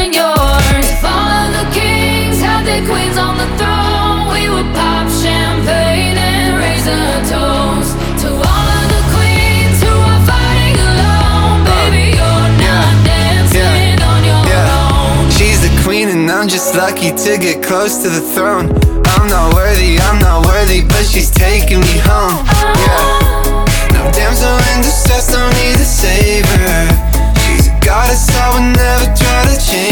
• Pop
power pop song